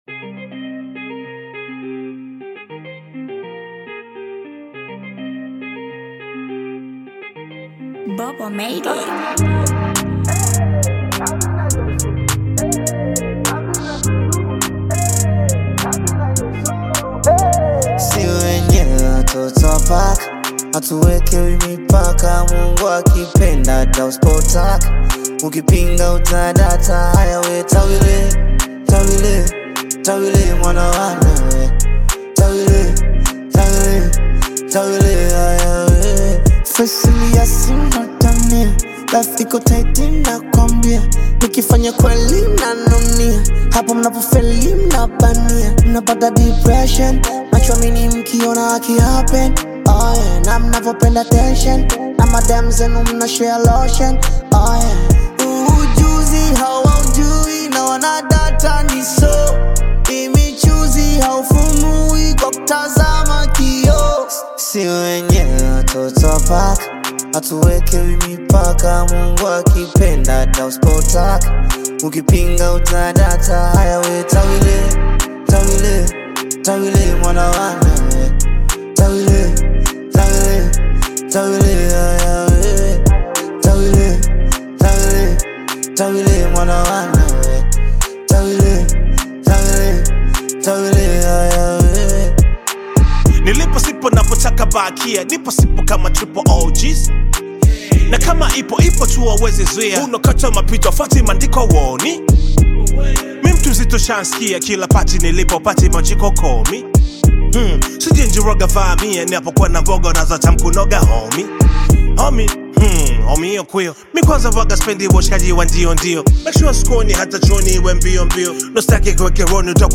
Tanzanian bongo flava artist, rapper and songwriter
Hip-Hop song